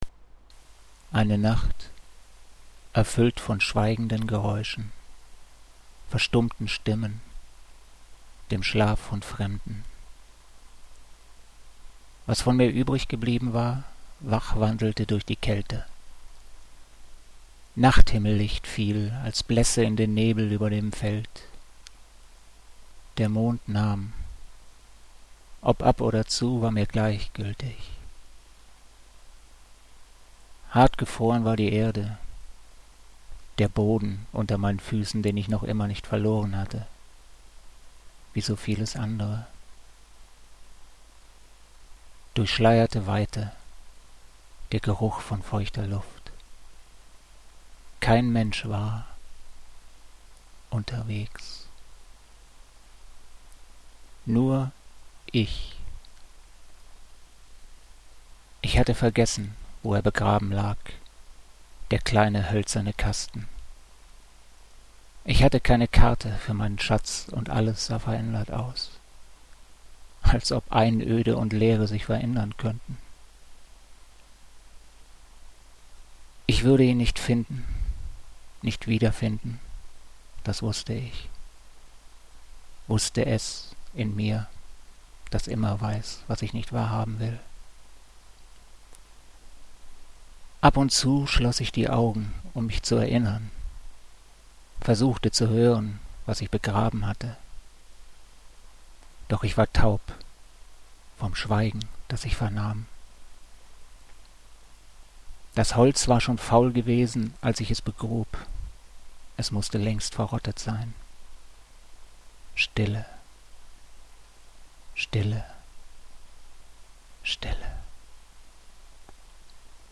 (Inwendig vorgetragen:)